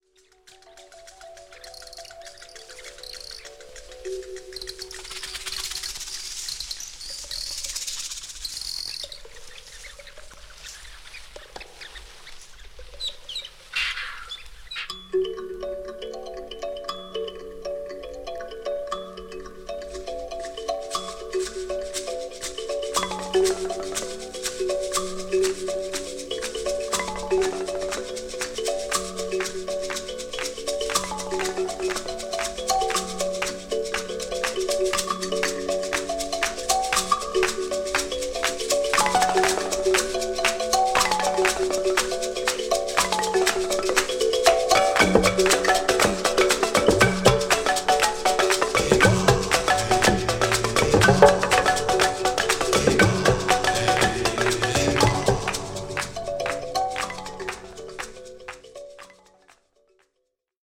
JAZZ / JAZZ FUNK / FUSION